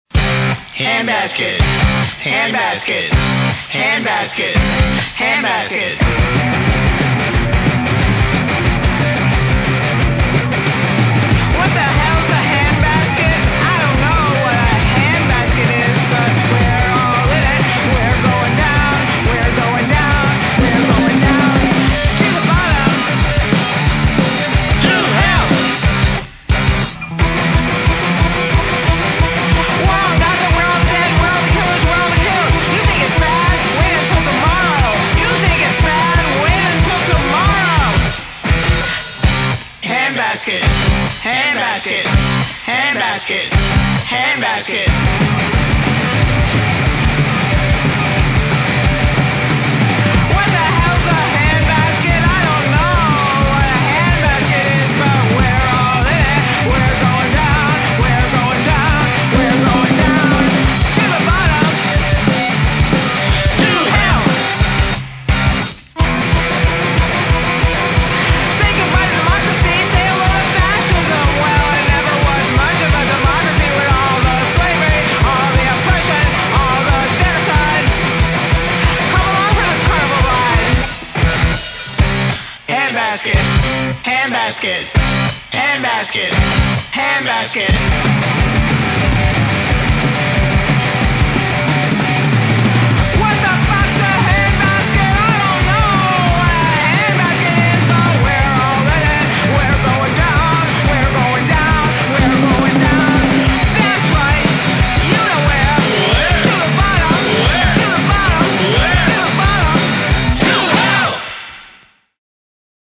Guitar and Lead Vocal
Drums, Percussion, and Backing Vocal